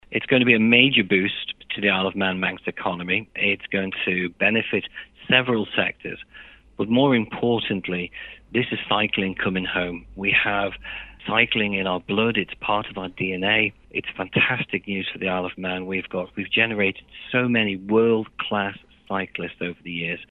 MHK Rob Callister, who's a member of the Department of Economic Development, says it will also provide a welcome boost to the Manx economy: